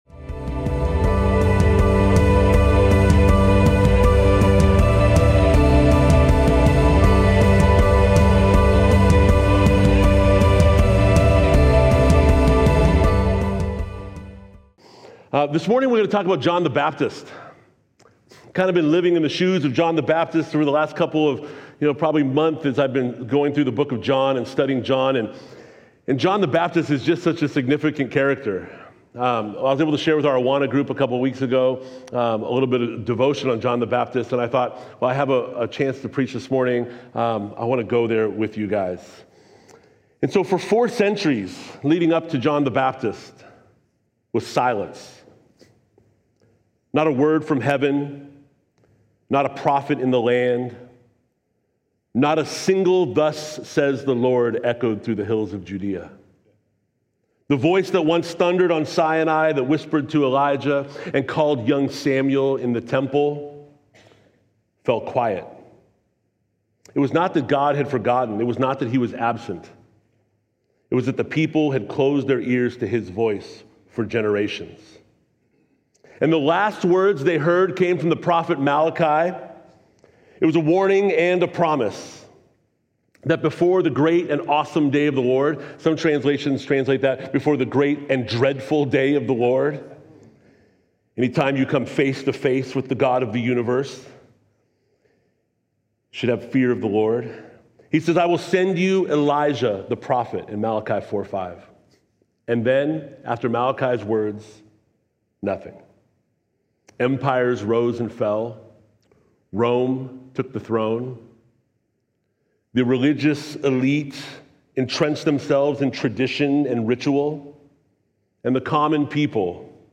This is an audio podcast produced by Calvary Chapel Eastside in Bellevue, WA, featuring live recordings of weekly worship services.
Sermon Sequence_FTP.mp3